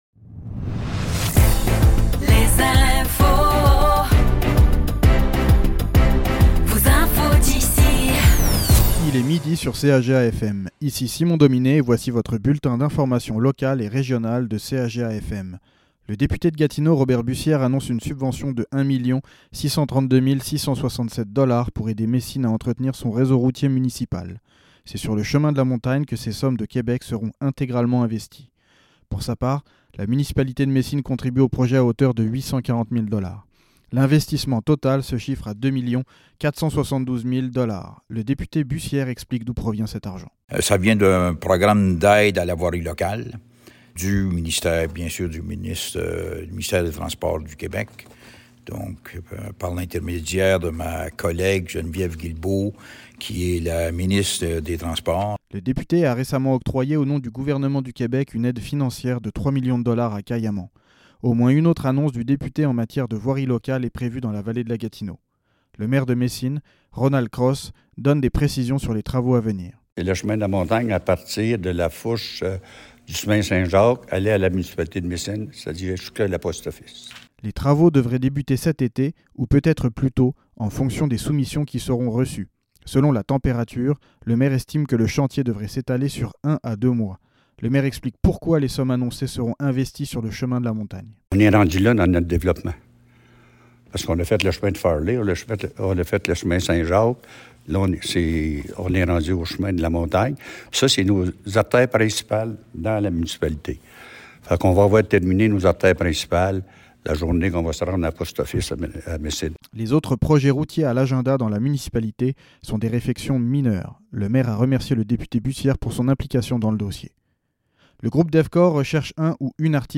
Nouvelles locales - 6 mars 2025 - 12 h